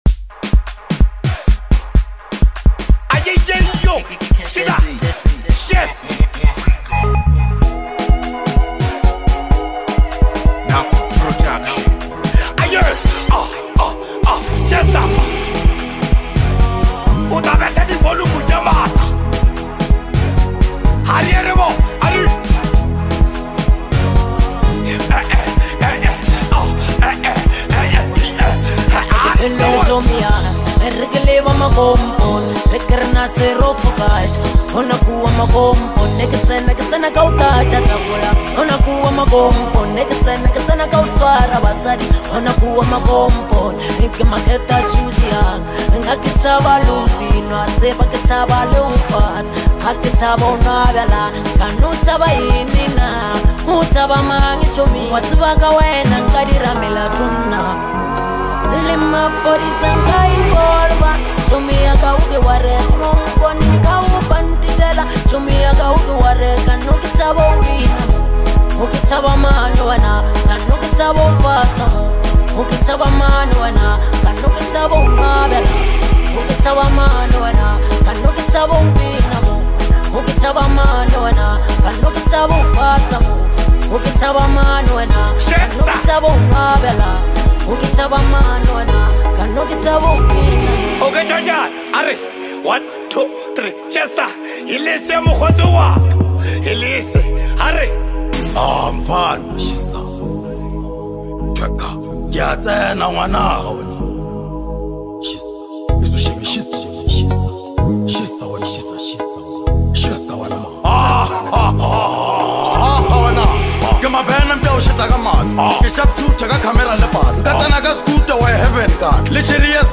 is a hard hitting Amapiano and Gqom fusion
bring raw,gritty lyricism